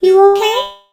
nani_kill_vo_01.ogg